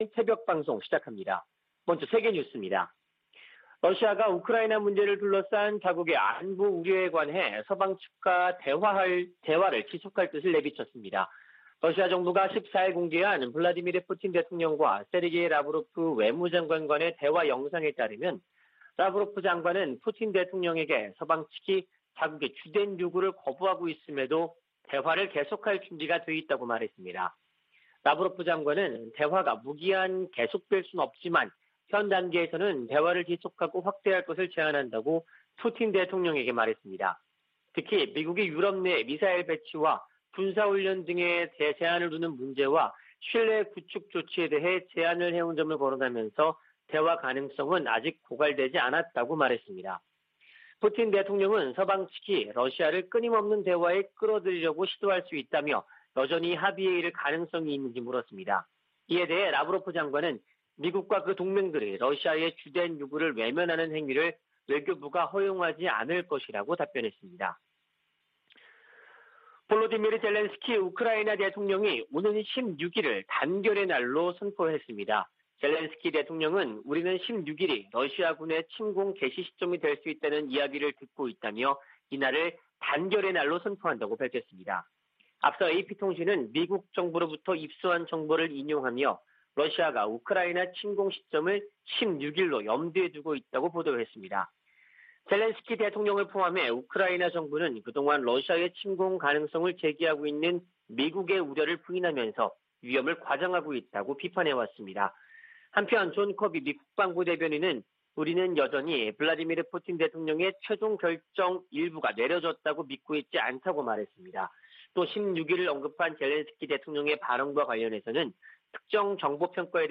VOA 한국어 '출발 뉴스 쇼', 2021년 2월 16일 방송입니다. 미 국무부는 북한 영변 핵 시설이 가동 중이라는 보도에 대해 북한이 비확산 체제를 위협하고 있다고 비판했습니다. 조 바이든 미국 대통령이 물러날 때 쯤 북한이 65개의 핵무기를 보유할 수도 있다고 전문가가 지적했습니다. 미한일이 하와이에서 북한 문제를 논의한 것과 관련해 미국의 전문가들은 3국 공조 의지가 확인됐으나, 구체적인 대응이 나오지 않았다고 평가했습니다.